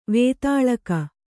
♪ vētāḷaka